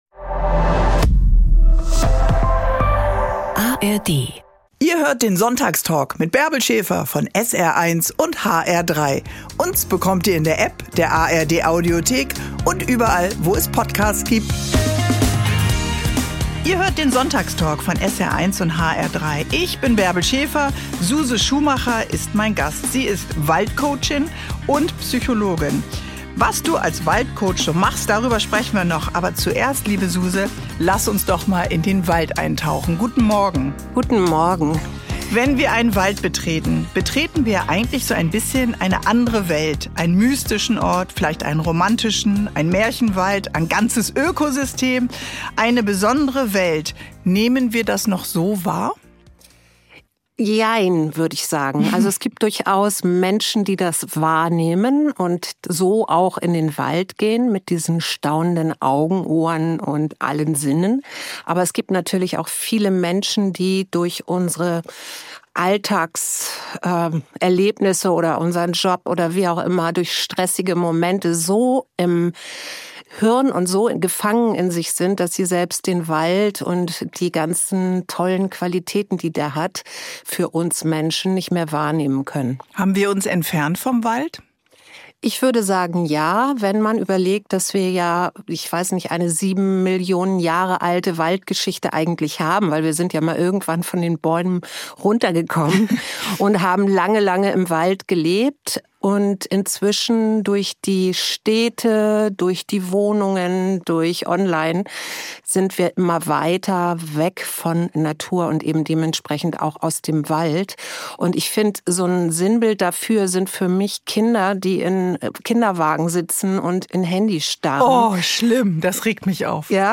Seit 25 Jahren sind die beiden ein Paar - mit einer dreijährigen Unterbrechung. Im Sonntagstalk mit Bärbel Schäfer geben sie Einblicke in das Auf und Ab einer Beziehung, in die Lust am Streit und die Kunst der Versöhnung.